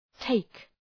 {teık}